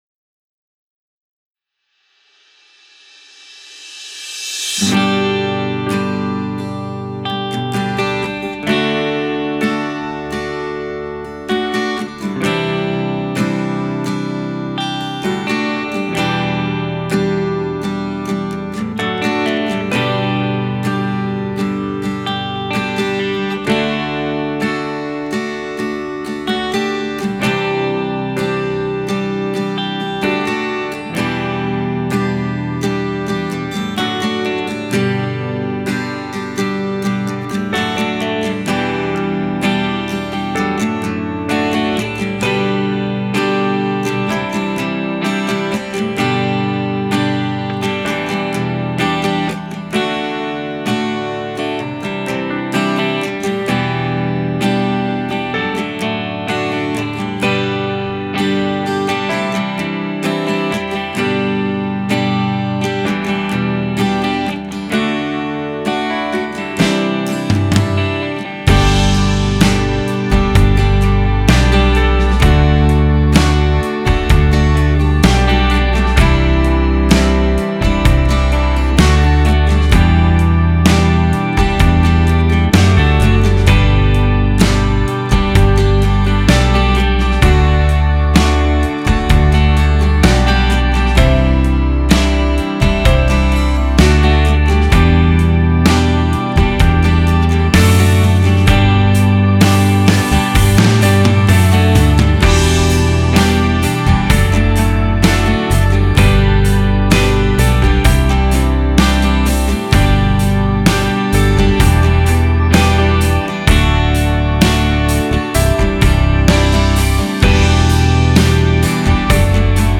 Категория: Поклонение